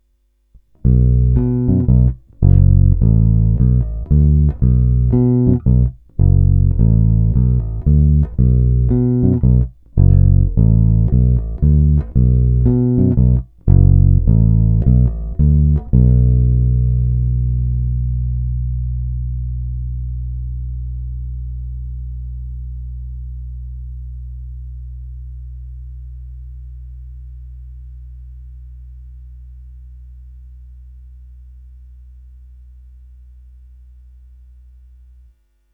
Čili jasný, zvonivý, průrazný s patrnou drzostí až agresivitou.
Není-li uvedeno jinak, jsou provedeny rovnou do zvukovky s plně otevřenou tónovou clonou a jen normalizovány, jinak bez dalších úprav.
Hra nad snímačem